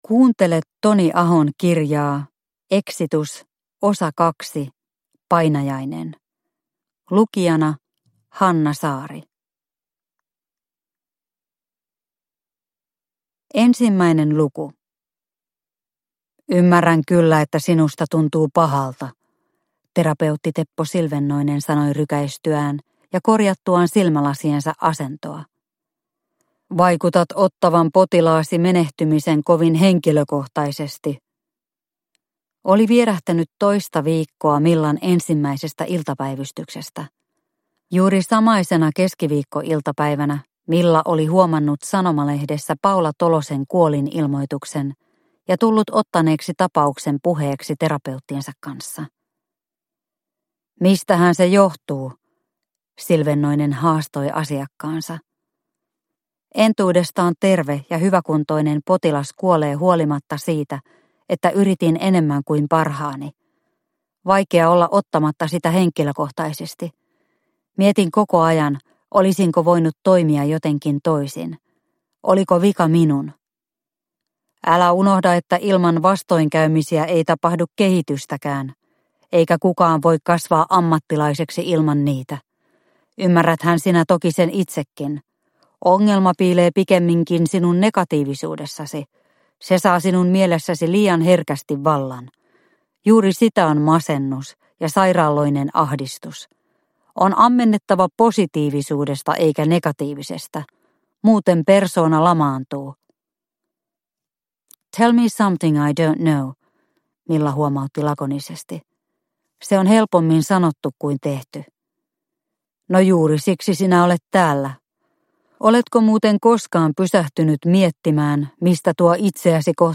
Exitus – Ljudbok – Laddas ner